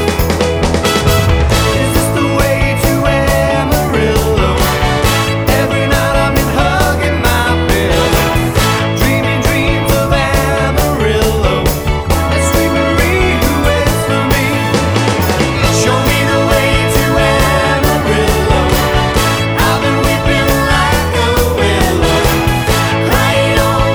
No guitar or Backing Vocals Pop (1970s) 3:20 Buy £1.50